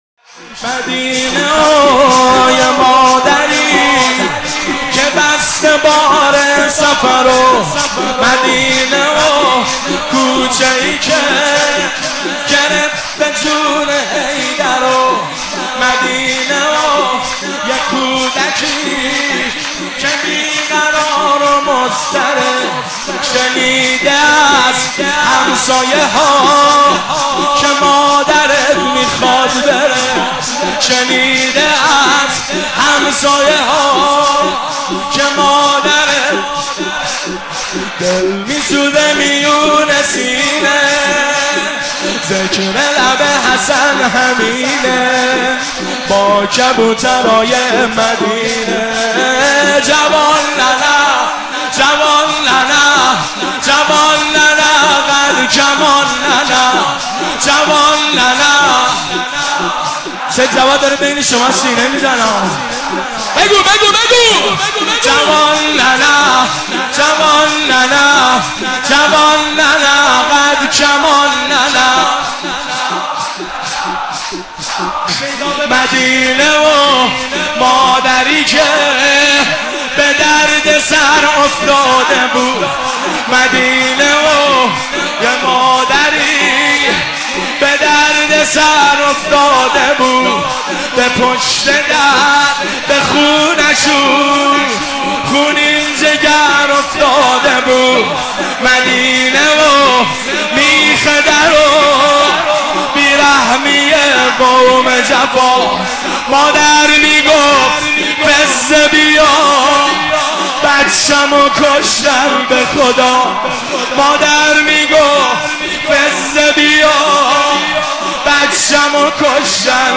مداحی السلام ای سرزمین خدائی(واحد)
شب سوم محرم 1391